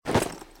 stand.ogg